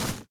default_grass_footstep.2.ogg